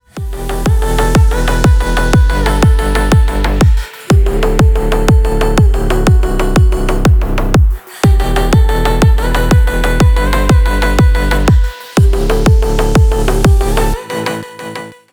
• Качество: 321, Stereo
громкие
мощные
deep house
Club House
slap house